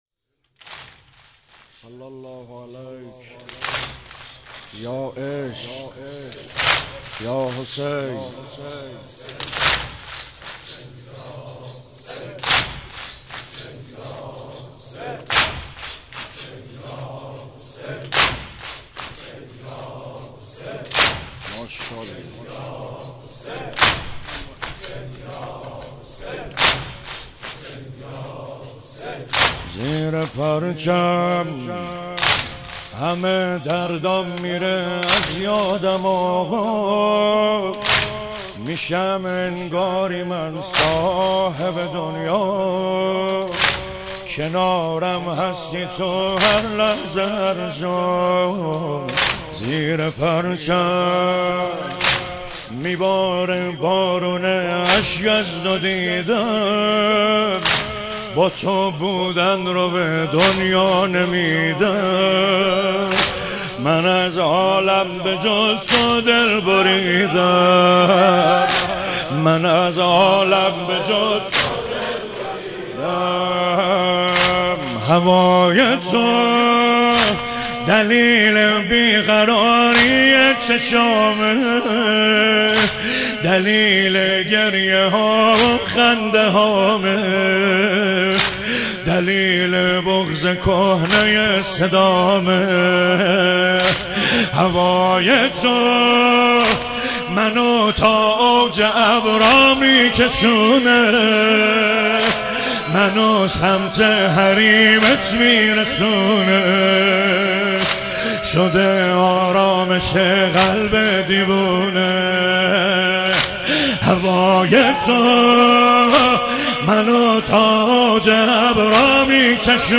محرم 98